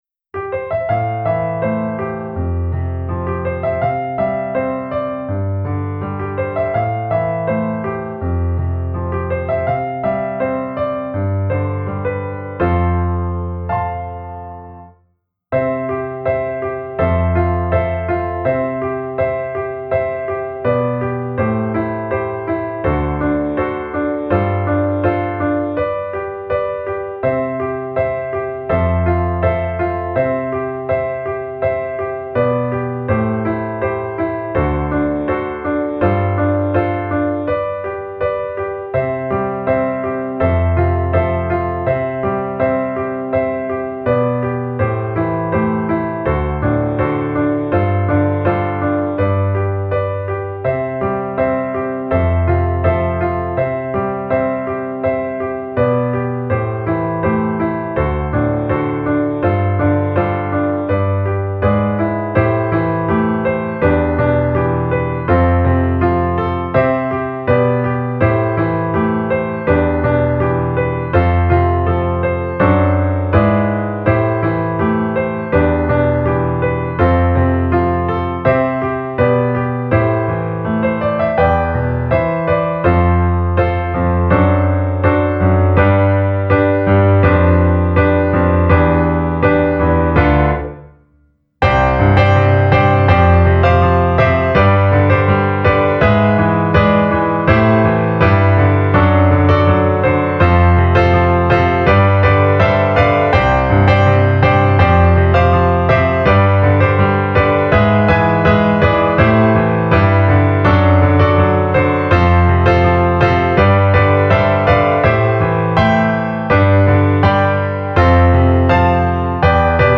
より弾きやすく歌声を合わせやすいよう、ピアノ楽譜を修正しました。
・ sna_mc4v2015_piano_ver0.4.mp3 (15329 ダウンロード)